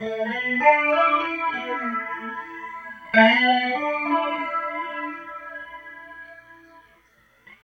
43 GUIT 4 -R.wav